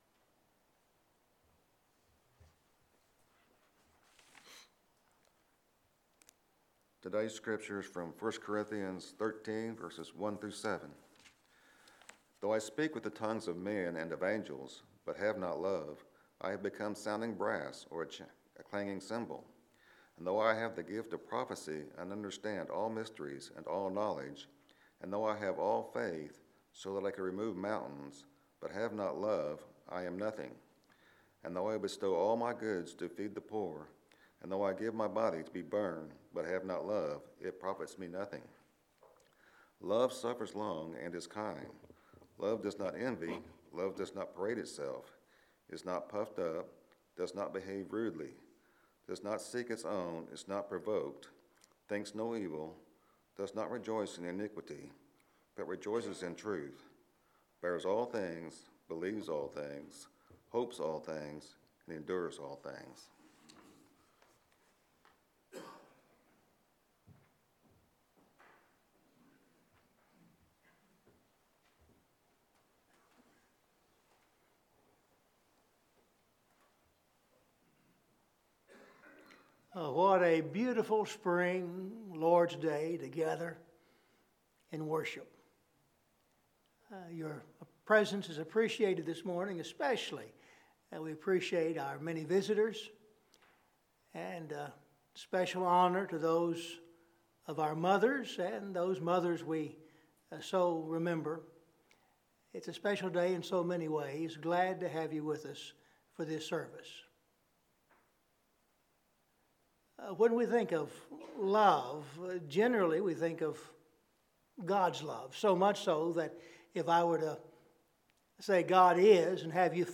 Scripture Reading – 1 Corinthians 13:1-7